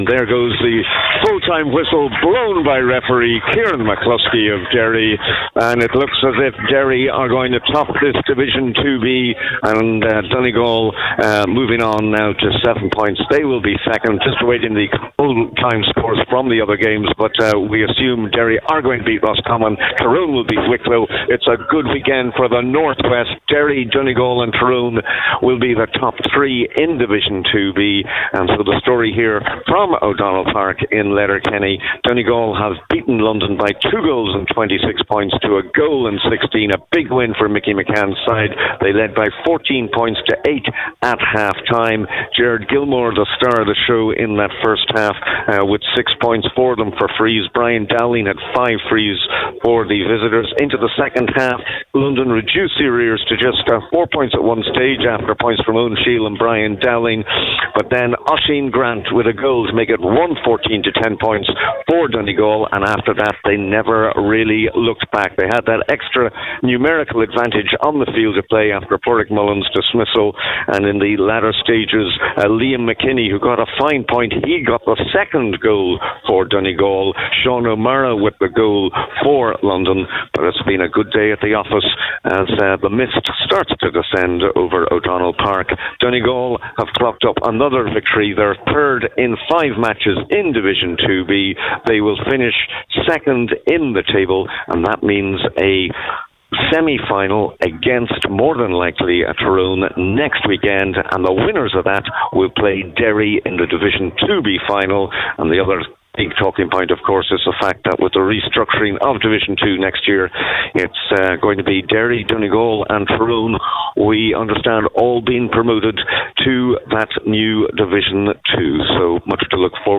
SUN-1703-DG-Hurling-full-time-report-edited.wav